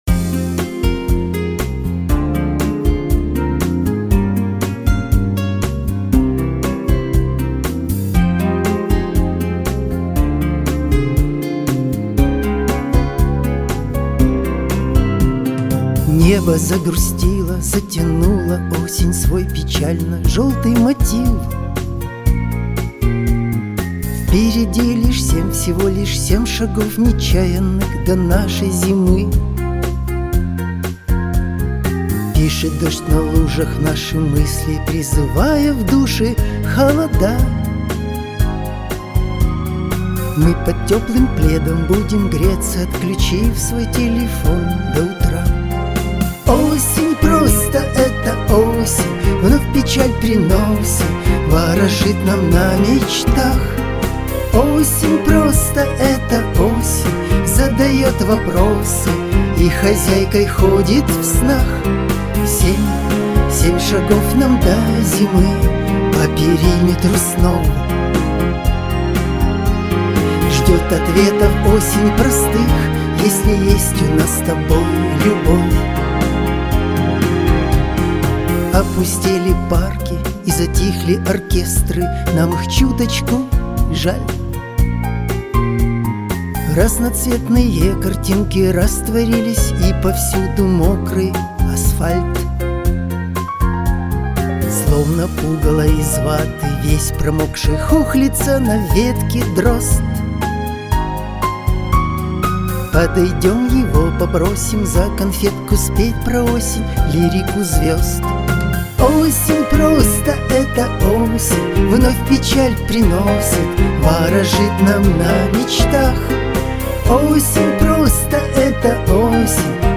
• Жанр: Легкая